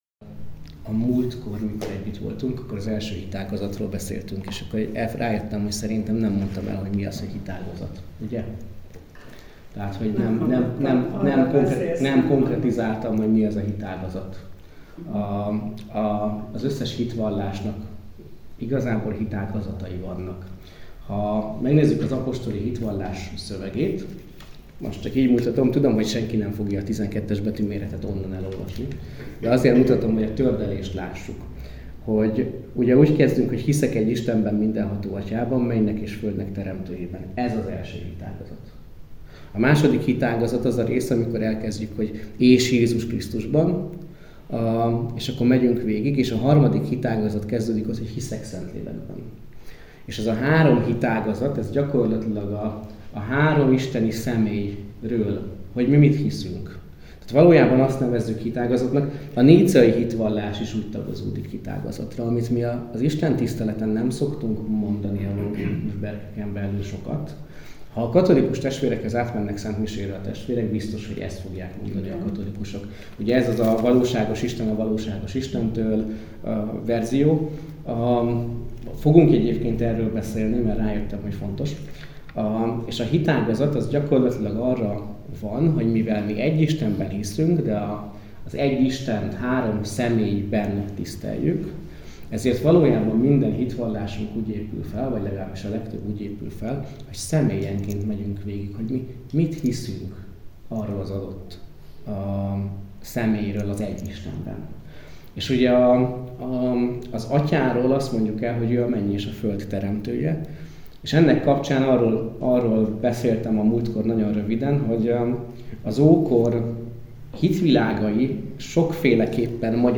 Apostoli hitvallás - Felnőtt hittan Hegyeshalomban